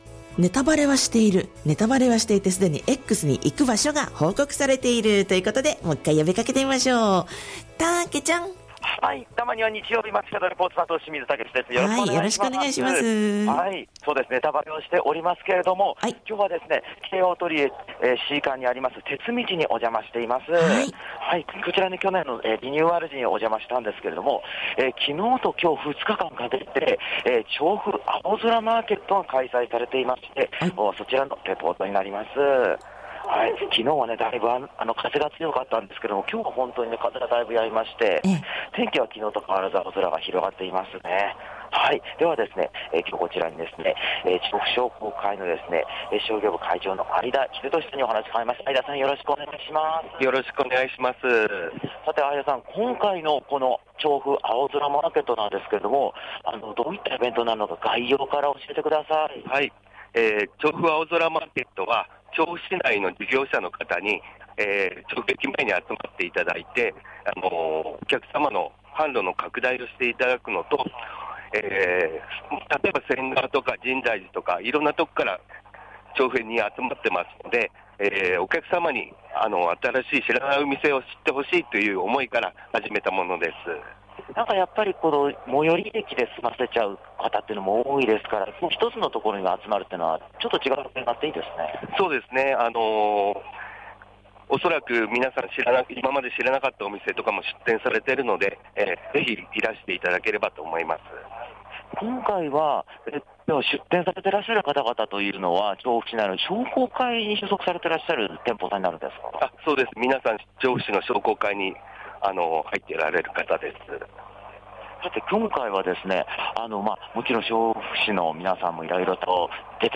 雲一つない青空の下からお届けした街角レポートは、
トリエ調布C館てつみちで開催中の「ちょうふ青空マーケット」の会場からお届けしました！